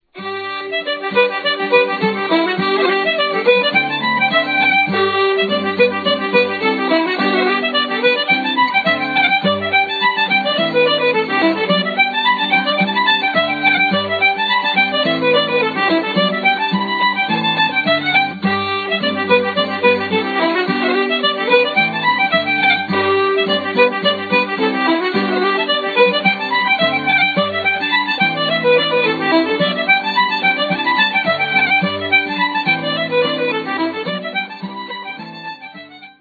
The ensemble playing is wonderful